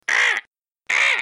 Download Vulture sound effect for free.
Vulture